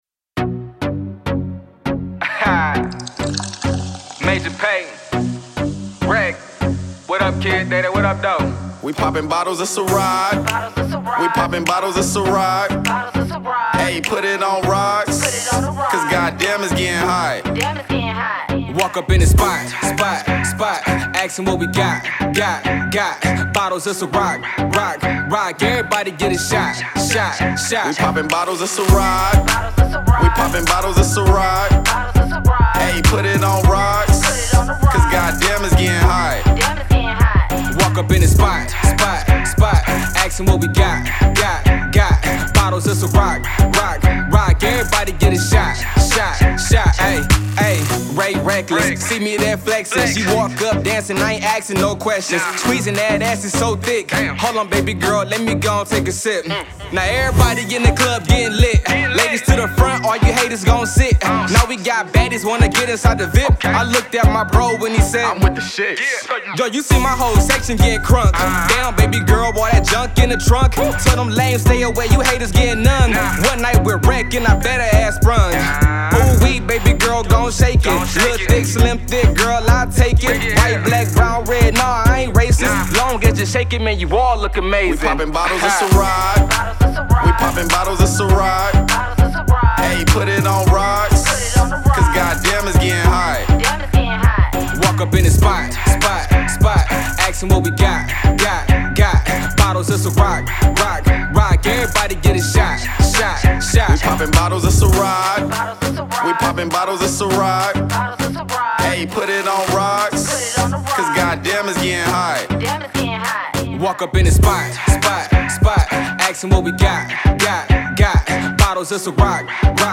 Hiphop
Club Banger